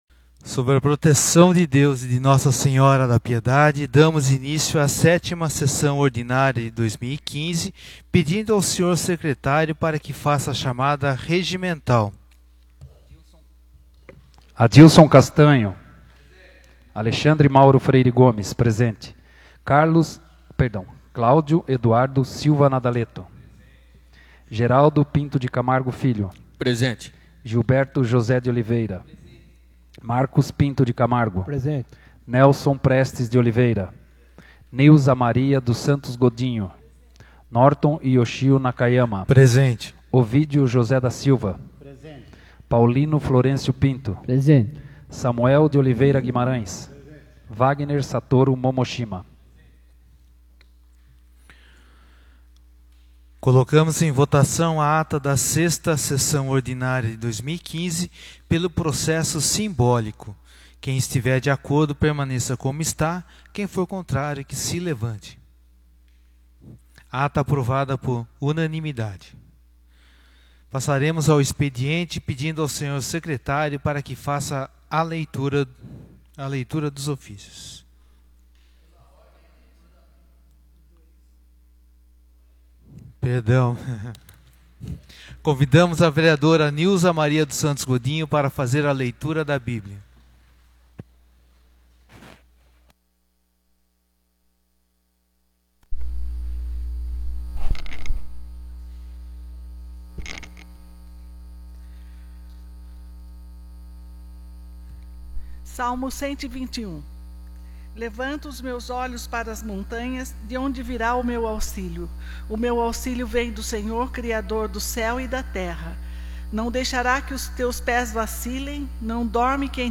7ª Sessão Ordinária de 2015